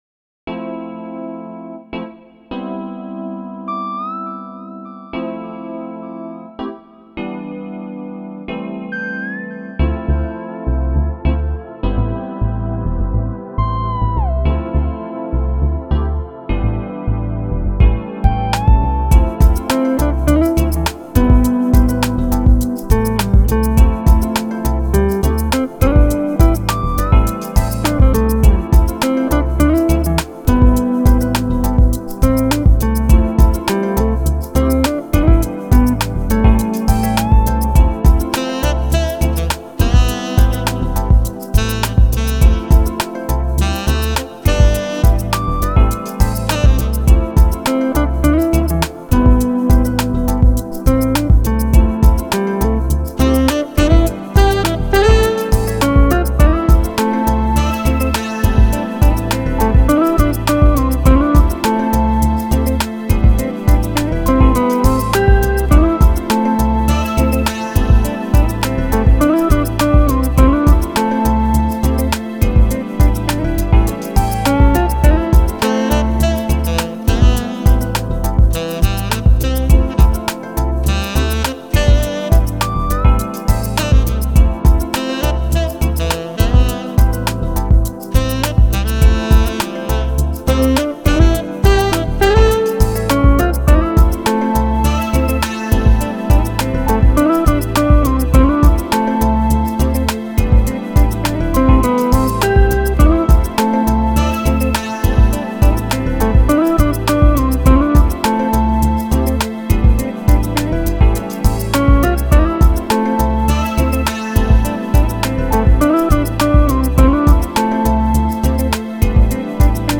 Genre: Smooth Jazz, Chillout, Longe